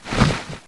Heroes3_-_Azure_Dragon_-_MoveSound.ogg